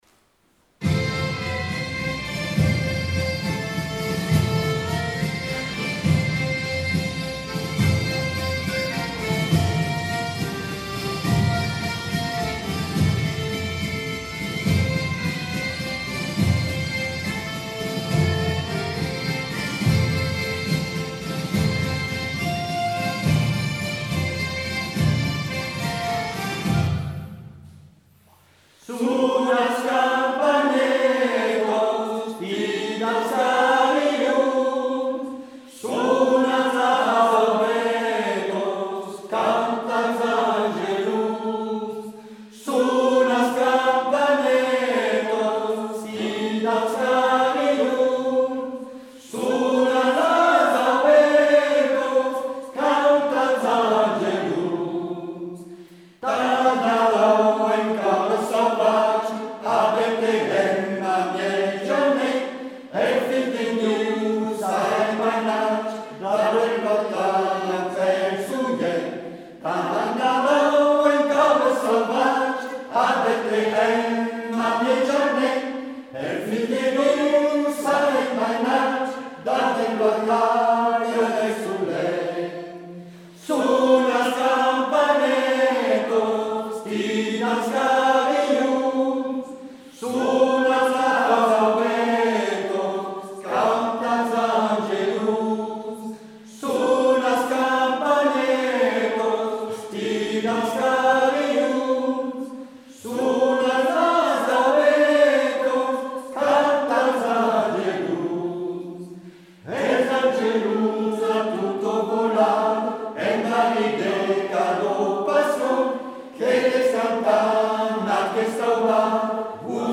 LA CHORALE OCCITANE "LA CAOUDIERENC" A ÉTÉ CRÉÉE A L’ORIGINE POUR CHANTER L’HYMNE DE CAUDIÈS « La Caoudierenc » ( Joseph ARMAGNAC ) A L’OCCASION DE LA PREMIÈRE FÊTE DU 6 AOÛT ORGANISÉE POUR CÉLÉBRER LA PREMIÈRE FÉDÉRATION DE FRANCE RÉUNIE A CAUDIÈS DE FENOUILLÈDES LE 6 AOÛT 1789.
Enregistrement du concert de Noël 2017 avec La Boulzane